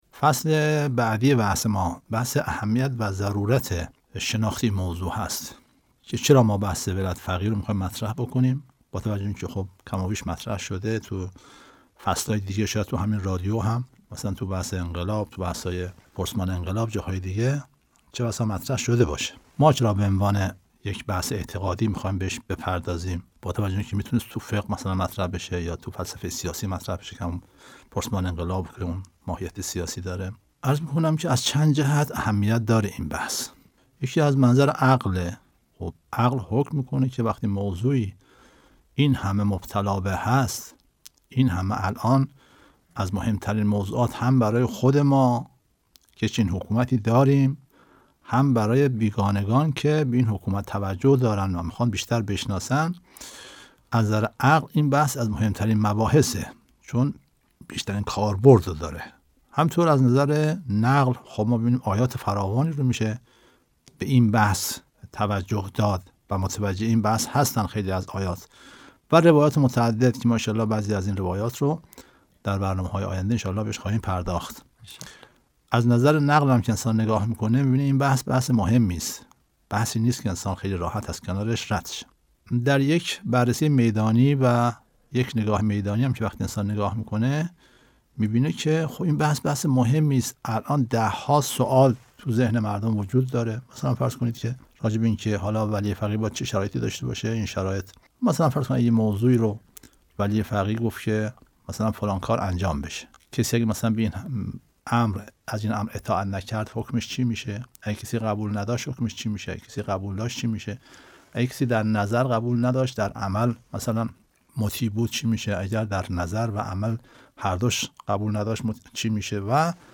مدت زمان سخنرانی : 6 دقیقه